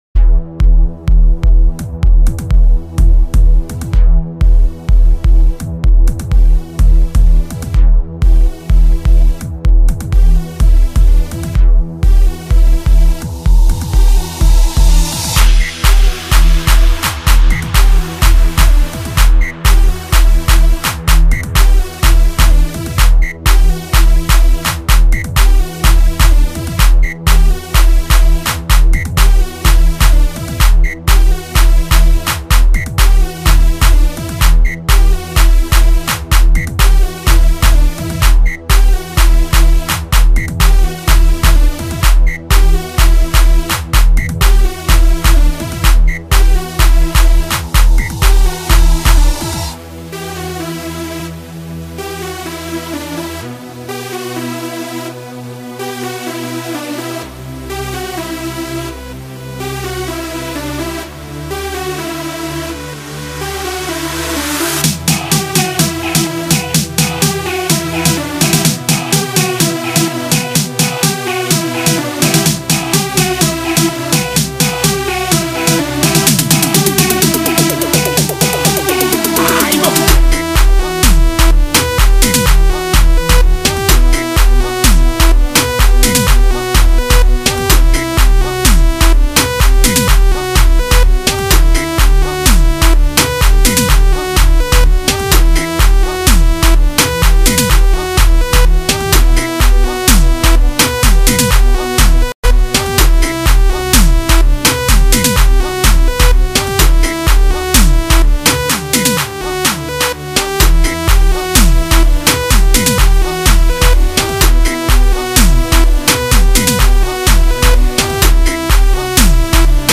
Gqom